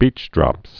(bēchdrŏps)